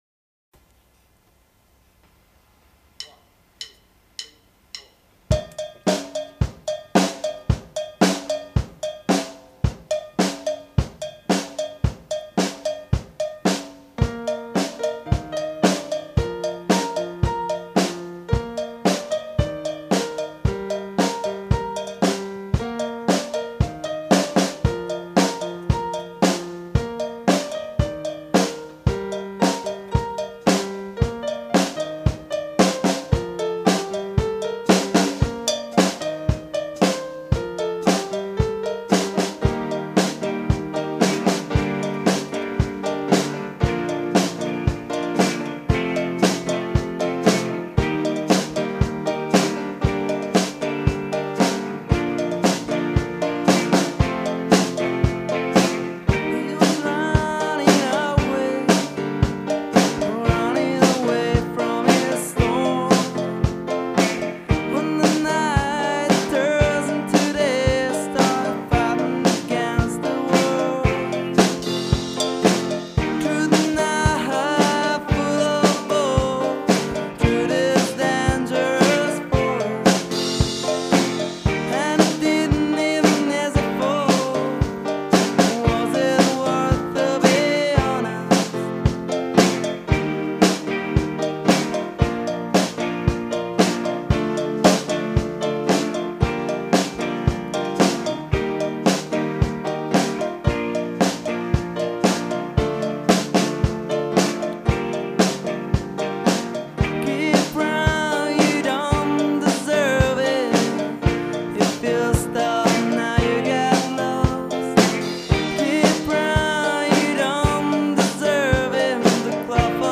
Genere: Rock Pop
tastiera/chitarra/voce
batteria
chitarra/voce/basso